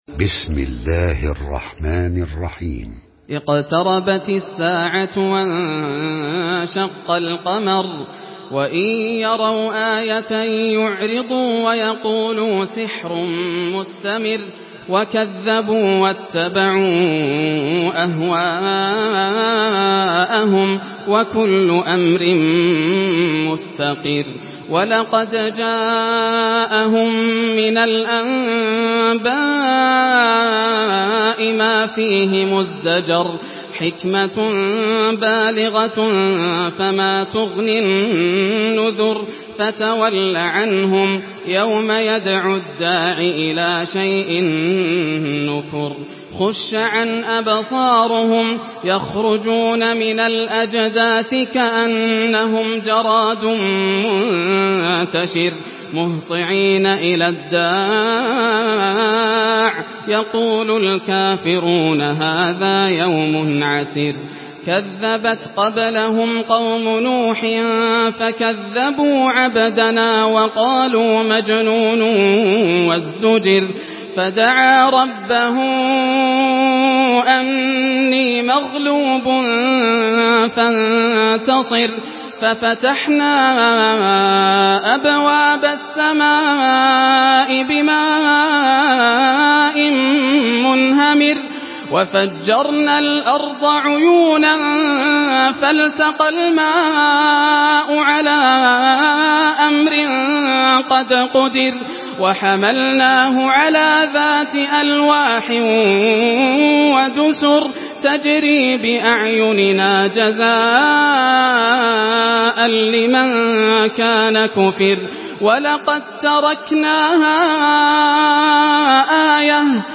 سورة القمر MP3 بصوت ياسر الدوسري برواية حفص
مرتل حفص عن عاصم